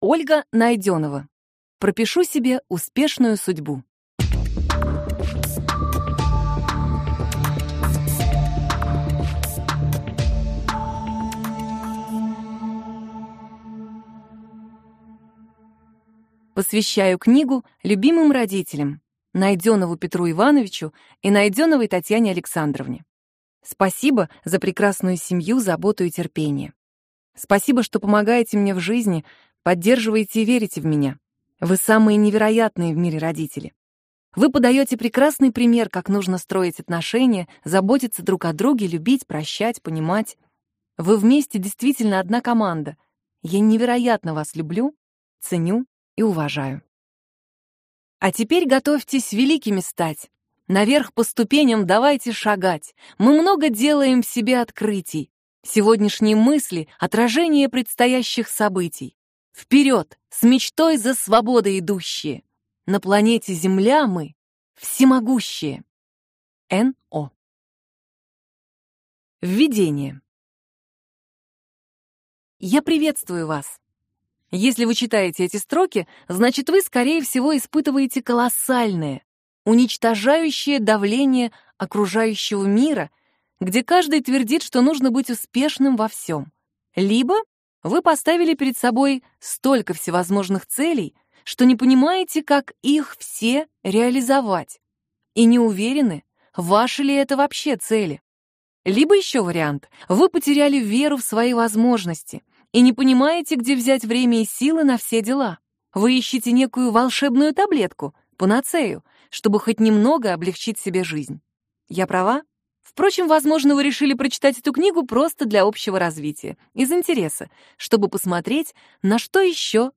Аудиокнига Пропишу себе успешную судьбу | Библиотека аудиокниг